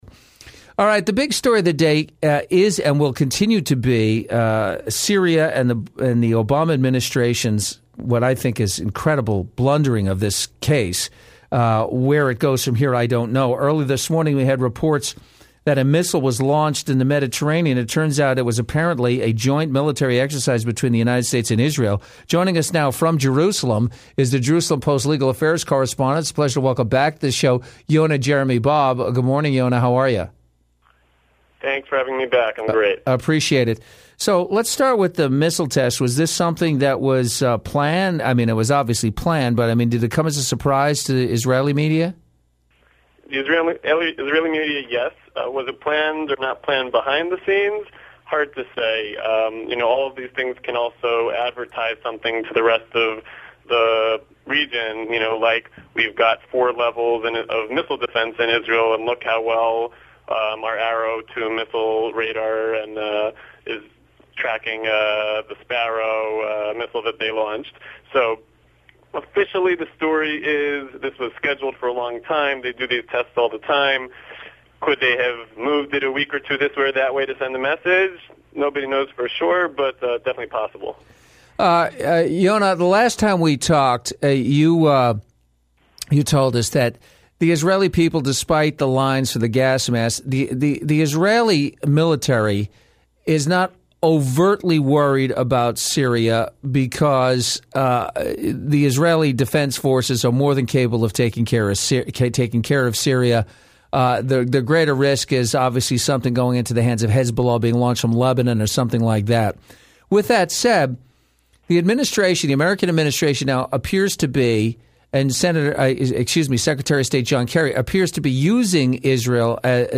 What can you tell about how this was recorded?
ABC Los Angeles Radio